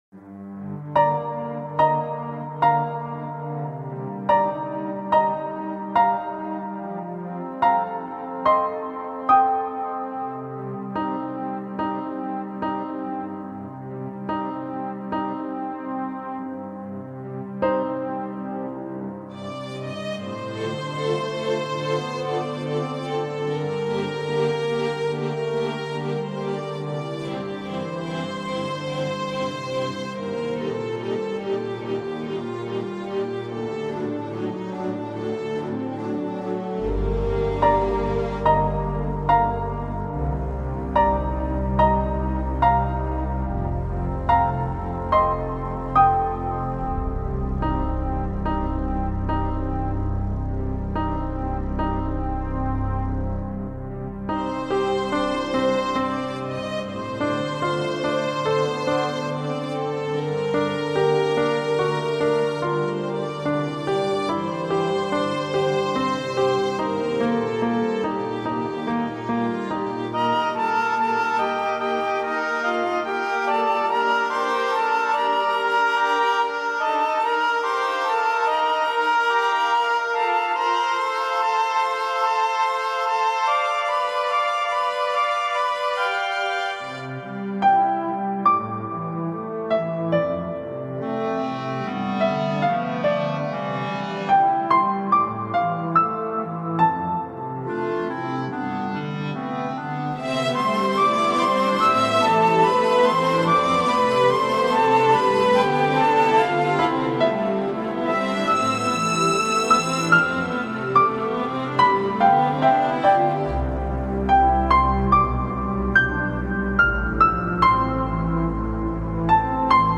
The piano echoes like someone calling.